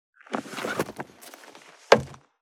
438物を置く,バックを置く,荷物を置く,トン,コト,ドサ,ストン,ガチャ,ポン,タン,
効果音室内物を置く